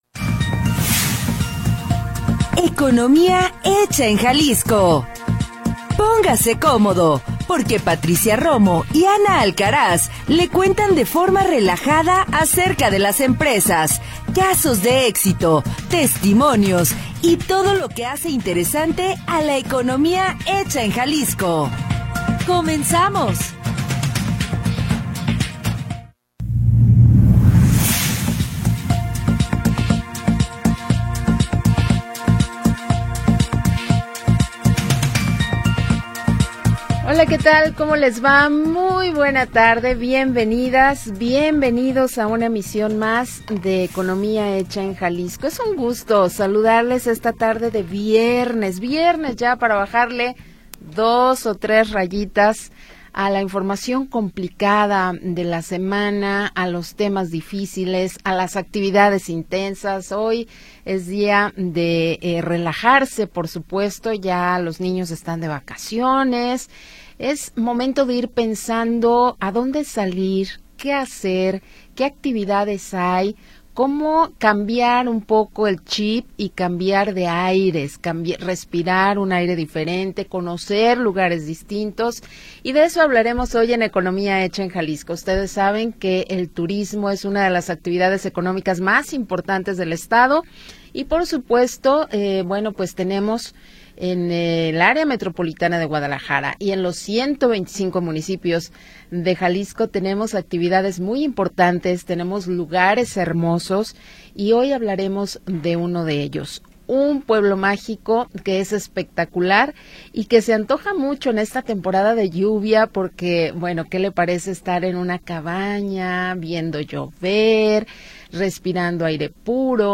le cuentan de forma relajada
Programa transmitido el 4 de Julio de 2025.